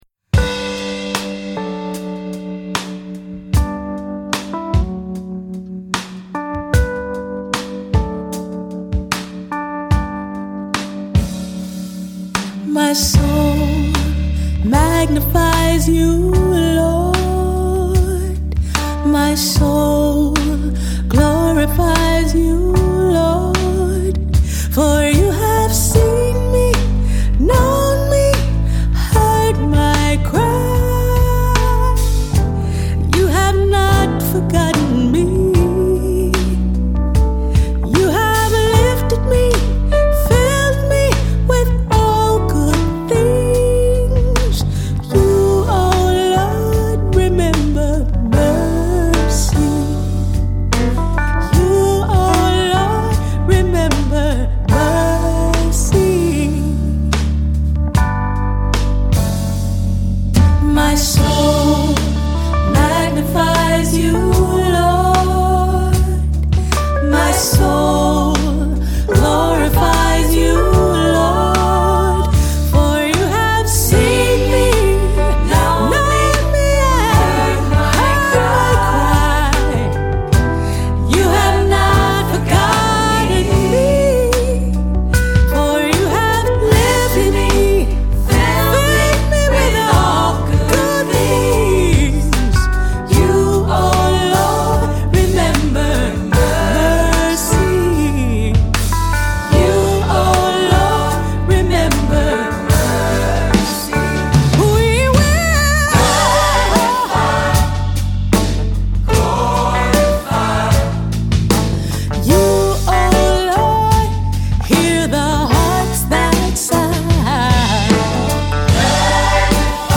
Another great new Advent Song by the worship band,